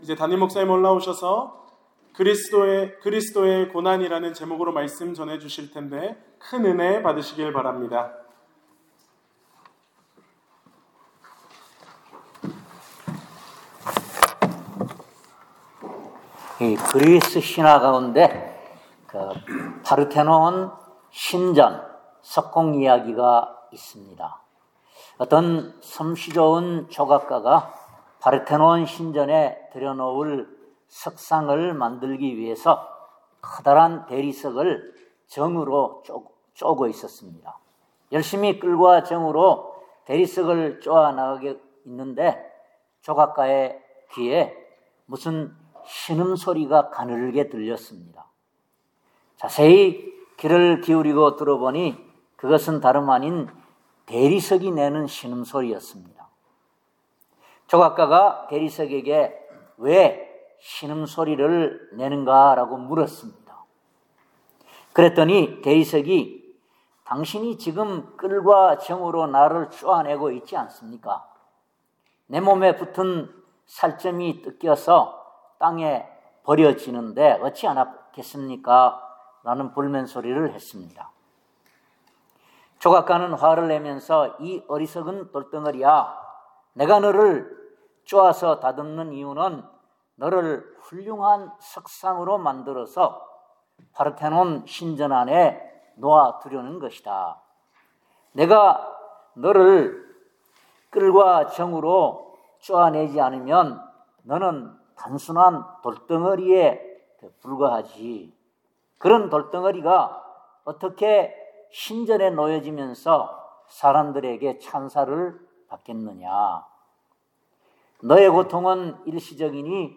Service Type: 주일예배